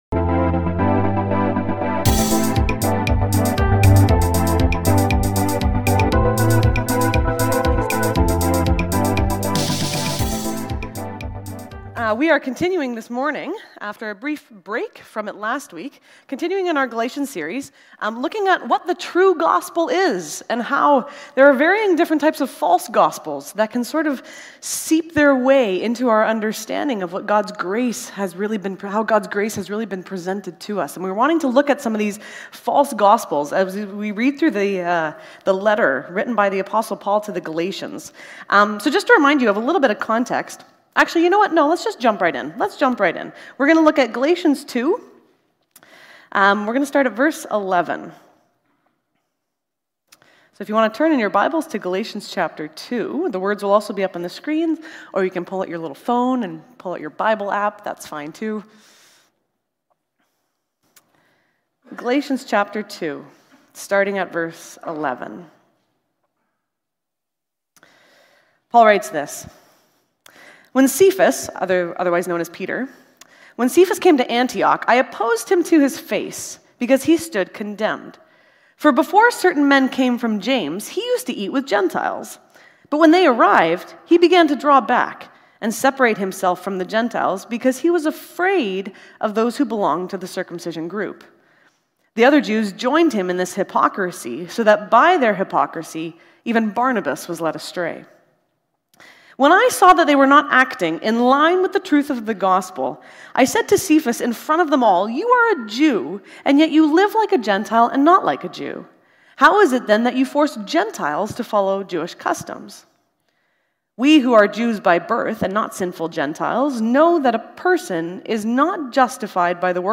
will continue the series with the sermon "The Gospel of Conformity" from Galatians 2:11-21.